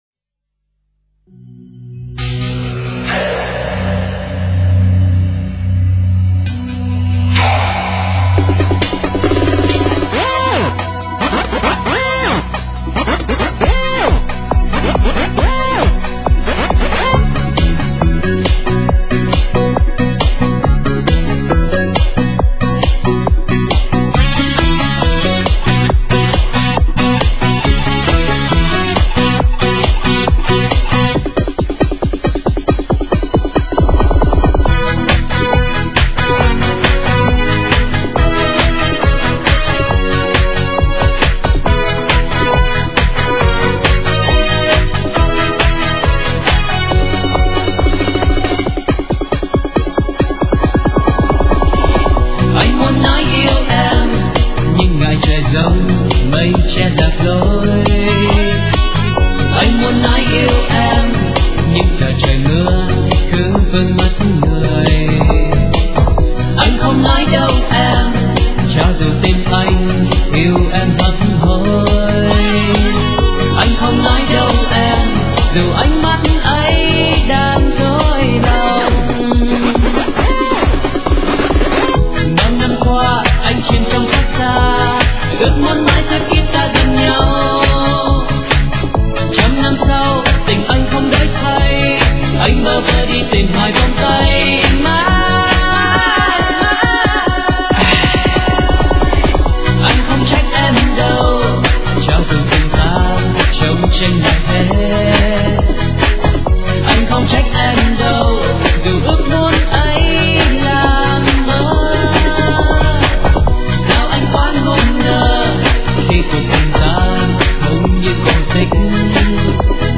* Thể loại: Nhạc Việt